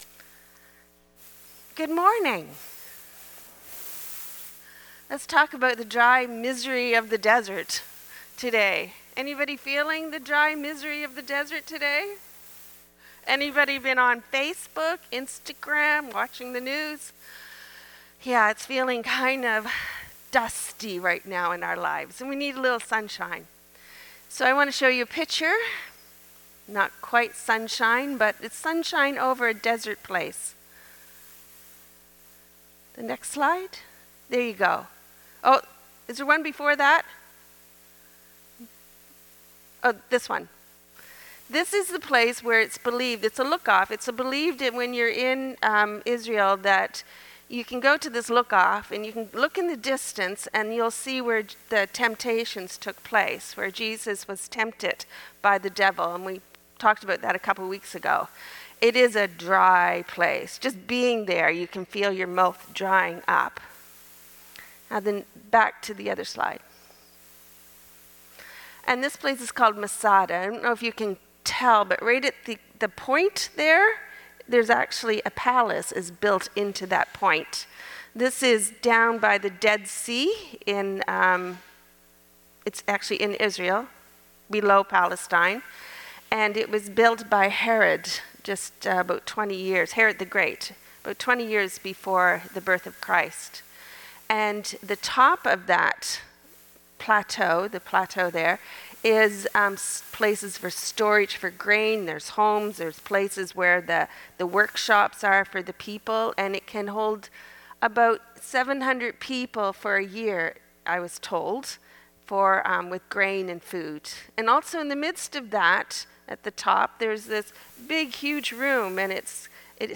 March 23rd, 2025 Sermon - The Dry Misery of the Desert